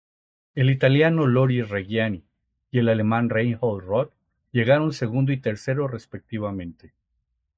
Pronunciado como (IPA) /seˈɡundo/